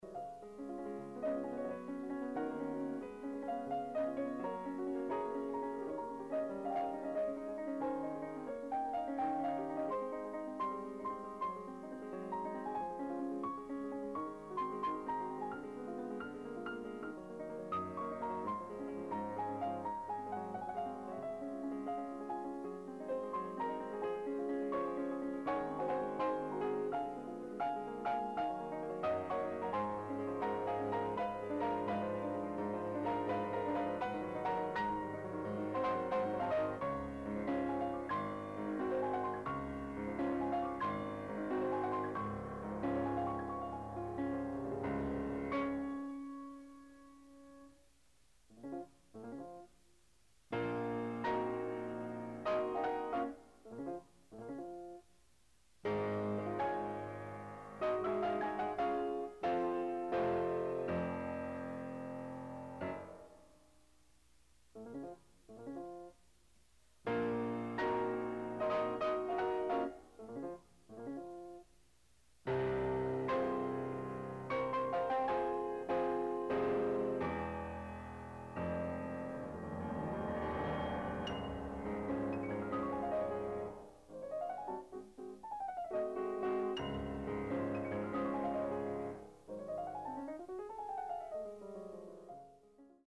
Additional Date(s)Recorded September 17, 1973 in the Ed Landreth Hall, Texas Christian University, Fort Worth, Texas
Sonatas (Piano)
Short audio samples from performance